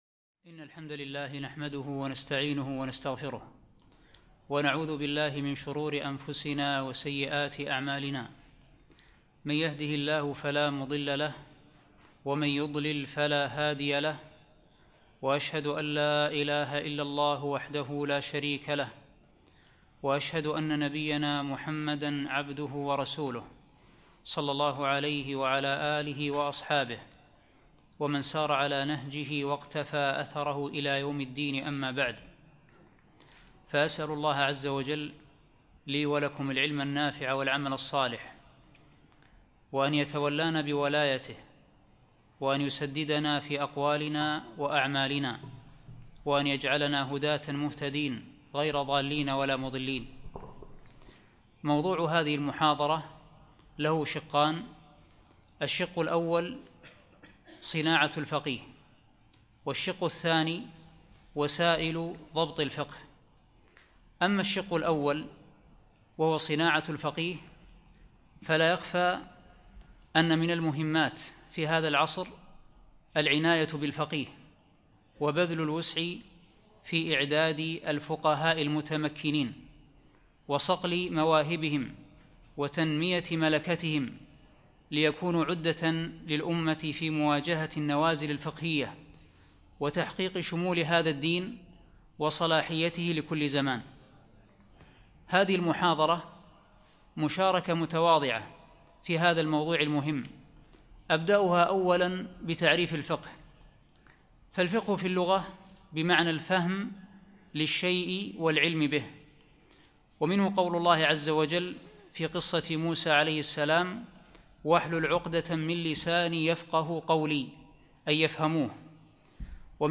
يوم الثلاثاء 8 جمادى ثاني 1438 الموافق 7 3 2017 في مسجد مضحي الكليب العارضية